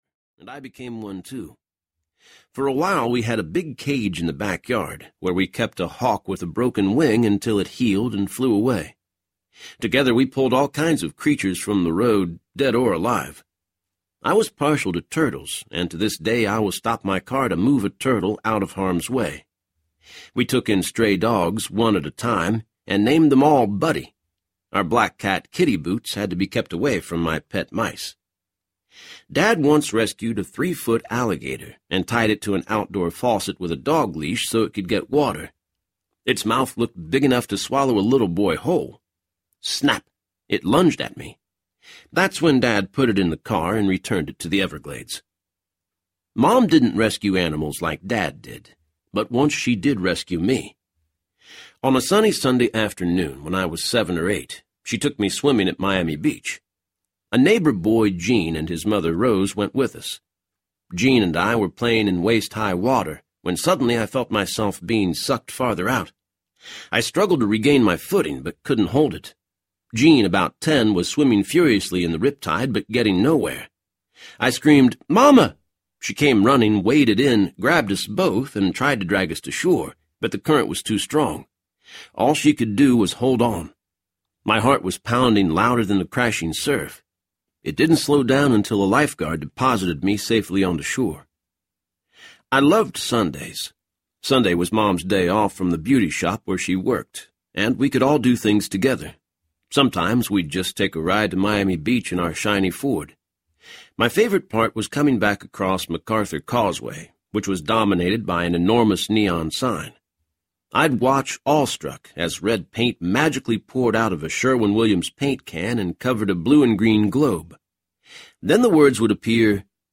In the Secret Service Audiobook
Narrator
8 Hrs. – Unabridged